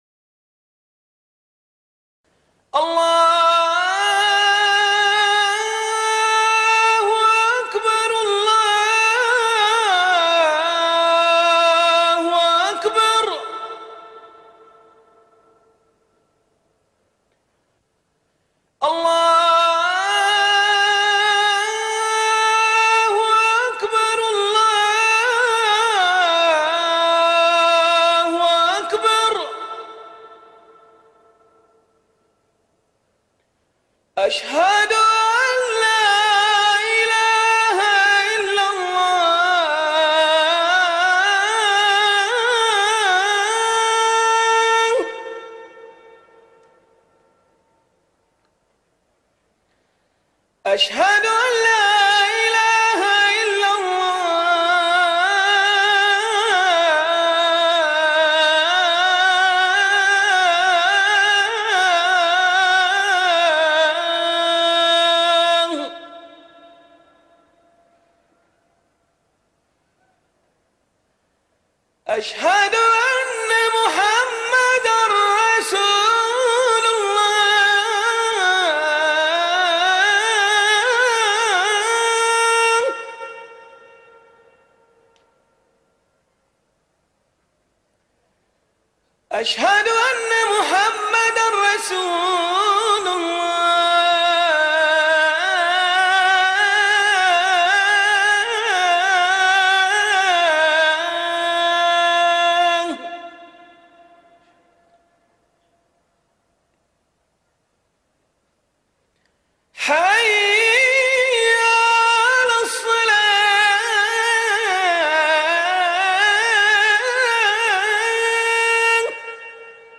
الأذان بصوت مؤذن من البحرين